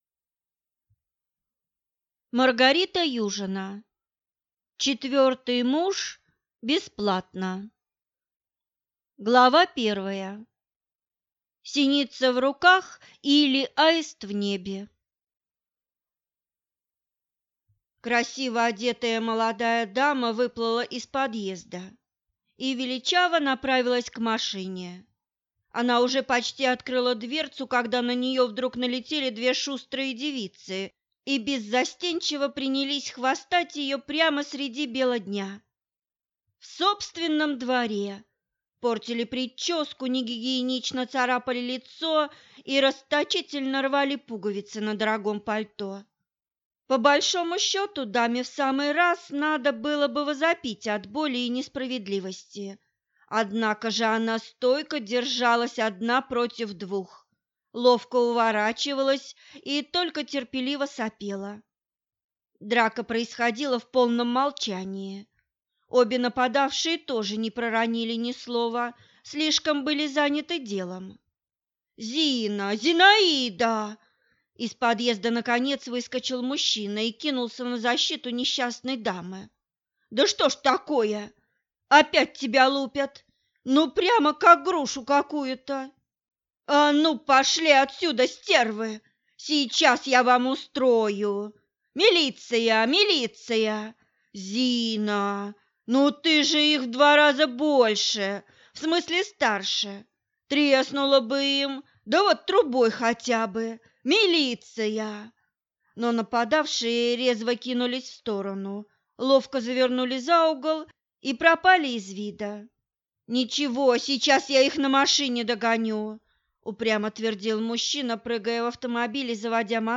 Аудиокнига Четвертый муж – бесплатно!